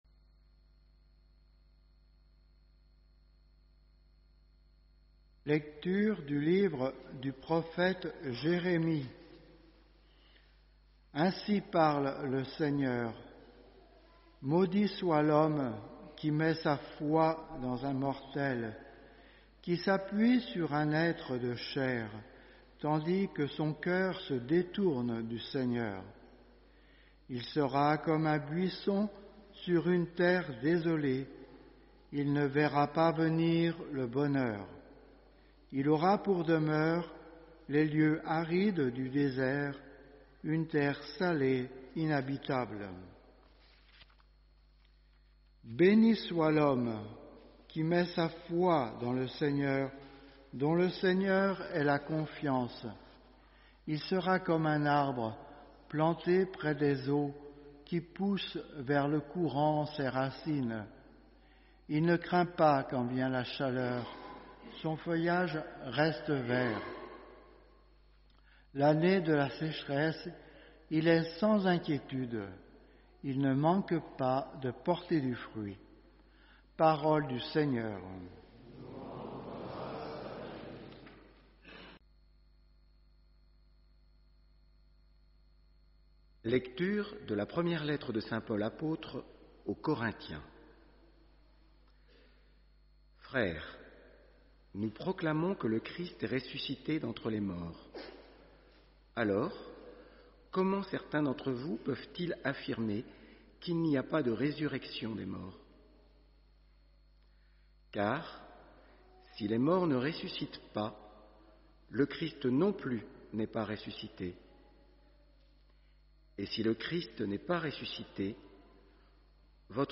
HOMÉLIE : MONSEIGNEUR BARBARIN Chers frères et sœurs, quatre dimanches de suite, nous lisons ce chapitre XV de l’épître aux Corinthiens […]